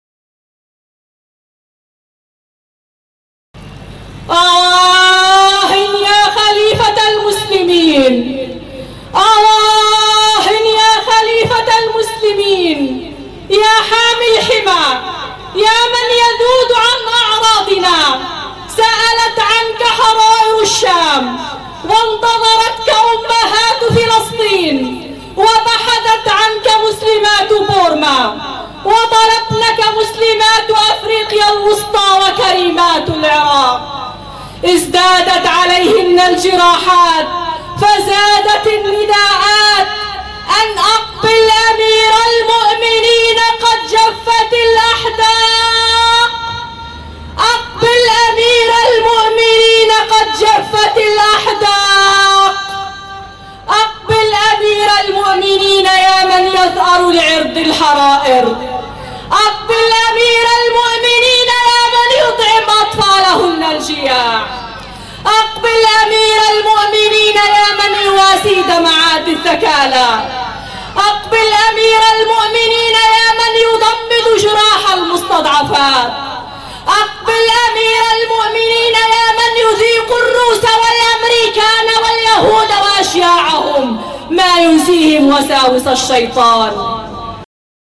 أبيات شعرية مقتطعة - آه يا أمير المؤمنين من كلمة النساء في مؤتمر الخلافة (حافظة للعرض) بغزة في الذكرى الـ93 لهدم الخلافة